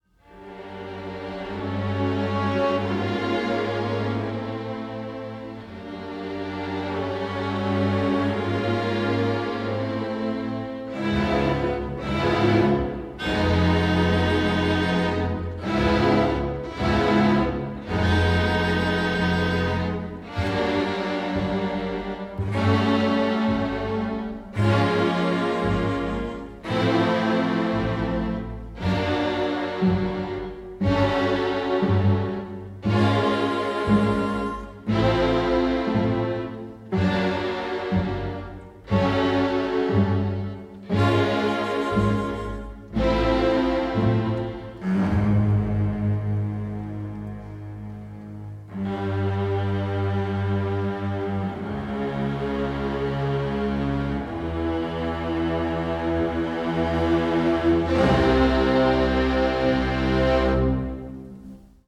original soundtrack
newly remastered